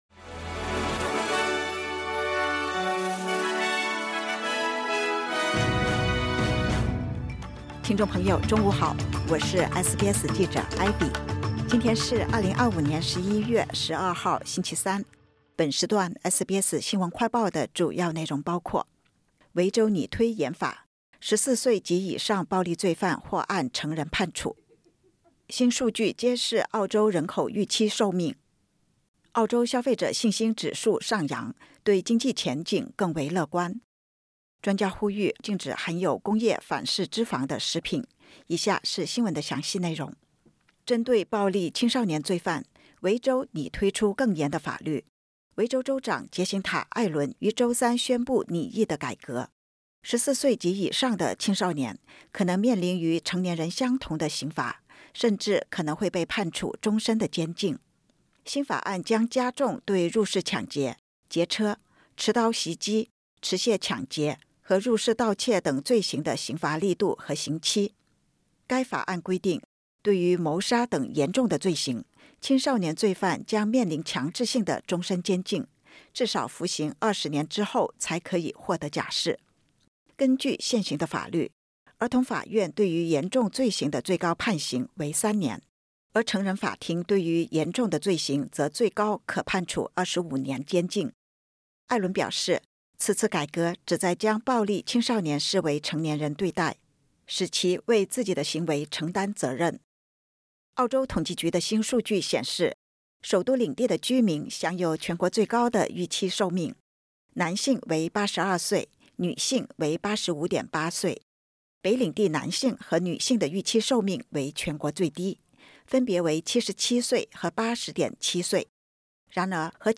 【SBS新闻快报】维州拟推严法 14岁及以上暴力罪犯或按成人判处